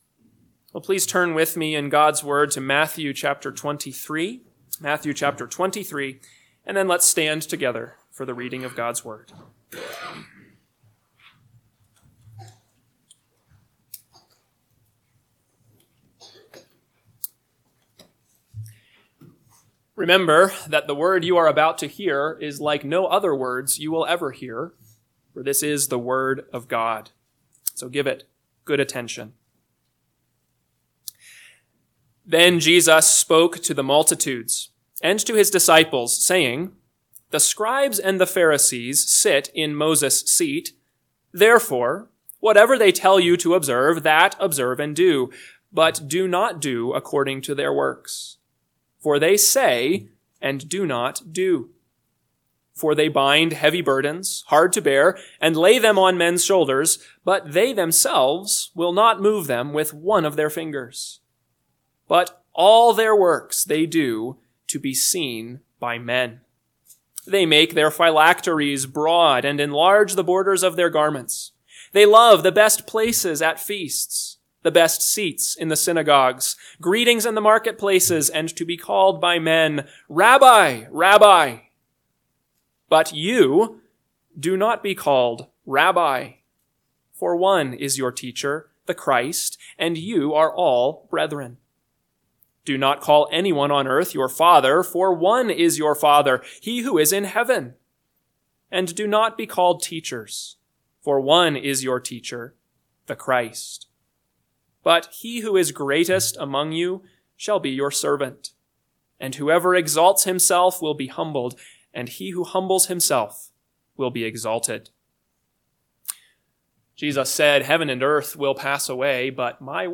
AM Sermon – 11/10/2024 – Matthew 23:1-12 – Northwoods Sermons